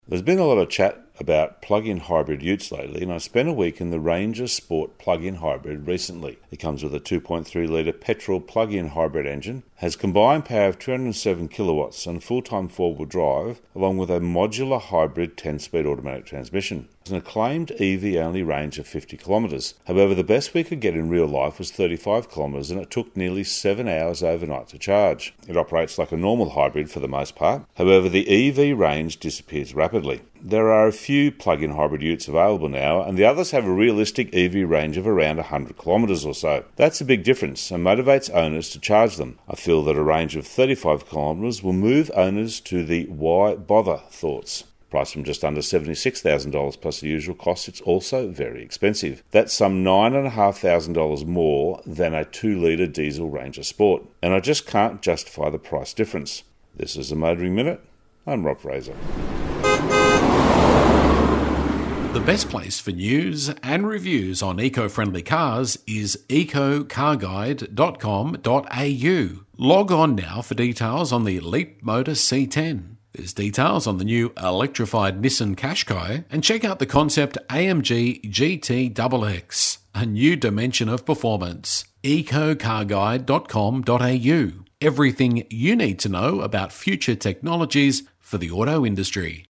Motoring Minute is heard around Australia every day on over 120 radio channels.